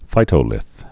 (fītō-lĭth)